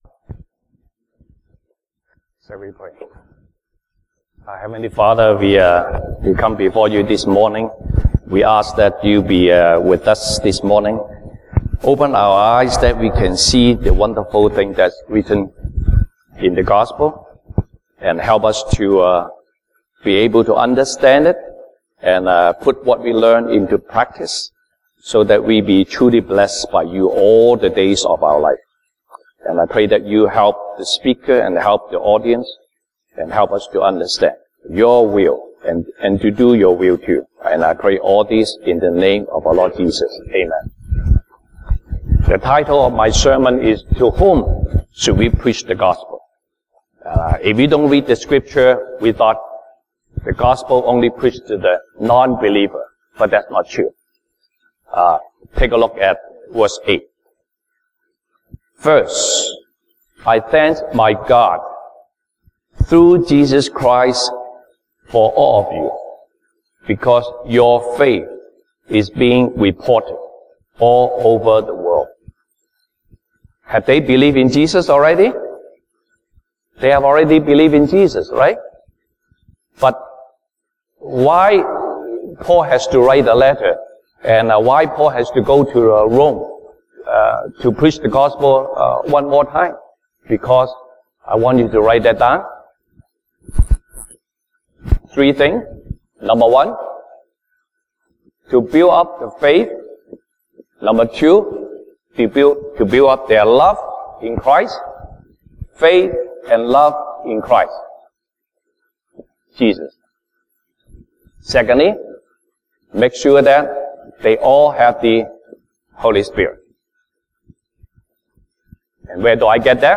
Sunday Service English Topics